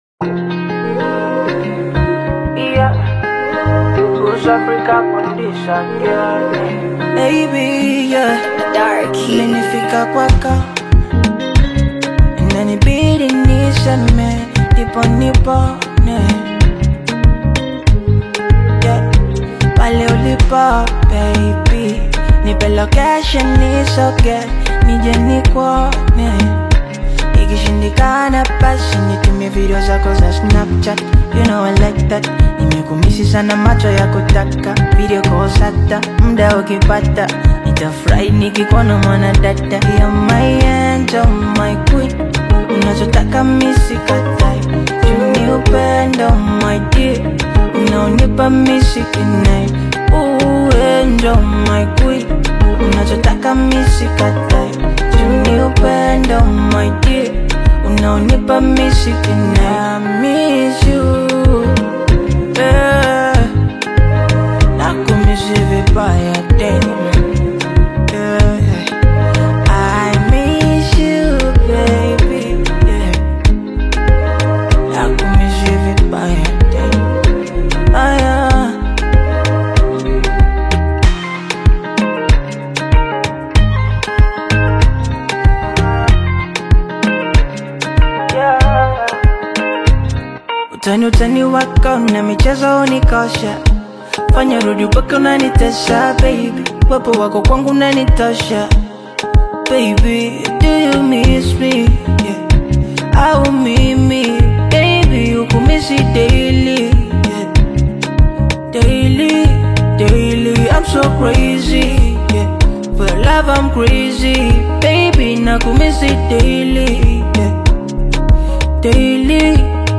soulful melodies